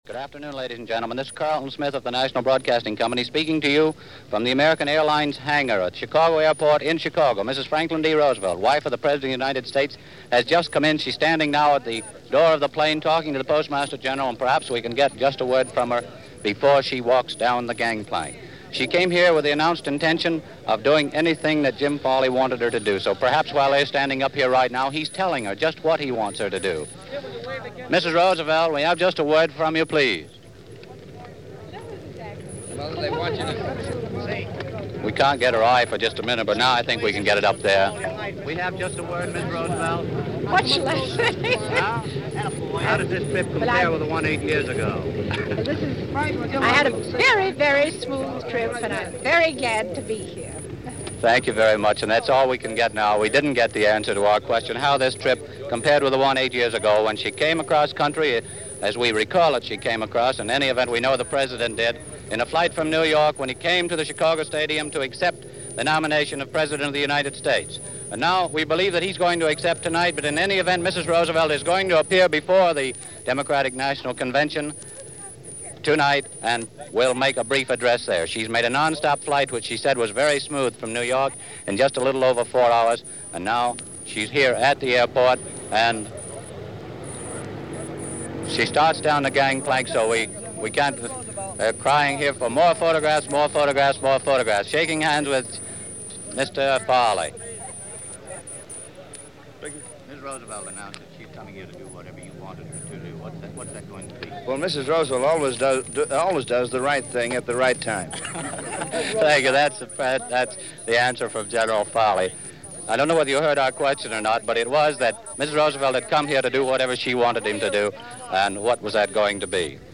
FDR For A Third Term - First Lady Arrives In Chicago - New Word In Our Lexicon: Vichy - July 18, 1940 News from NBC.